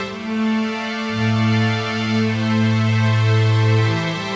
Index of /musicradar/80s-heat-samples/110bpm
AM_80sOrch_110-A.wav